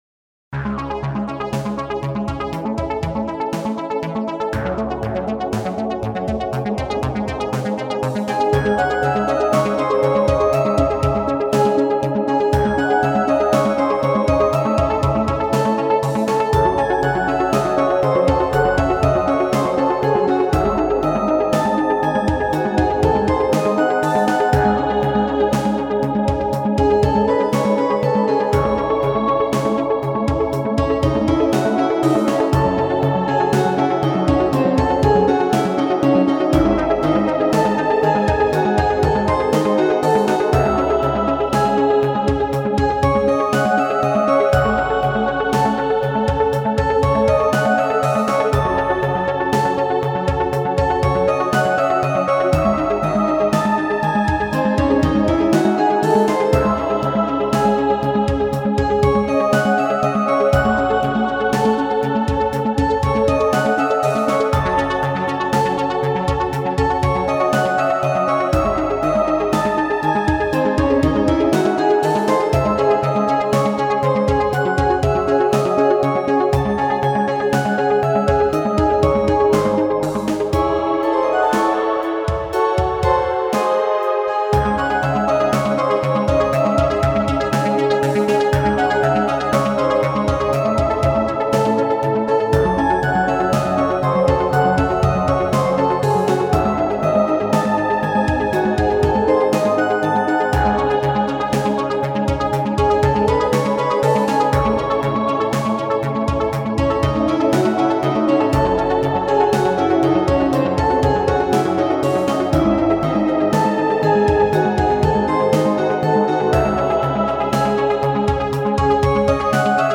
Meditative
Enigmatic
Neoclassical
Ambient
Electronic
Newage